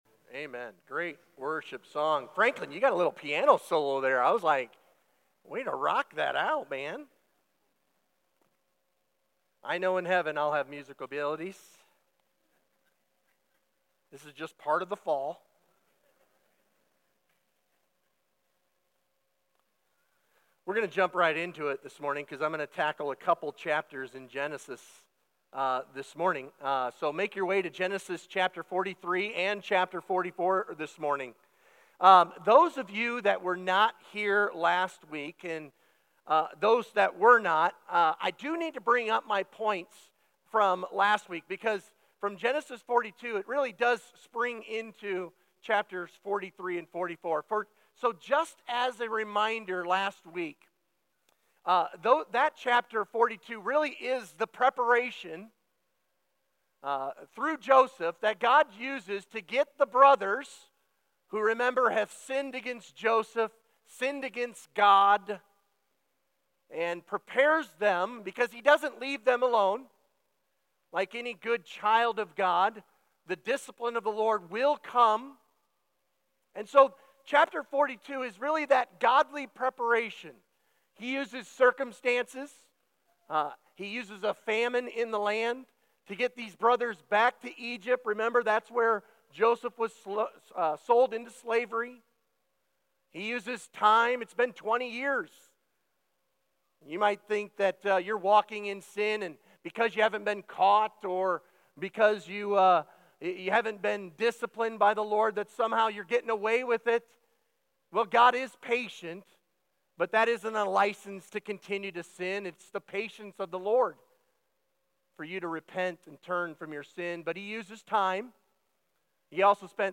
Sermon Questions: Read Genesis 42:21–22.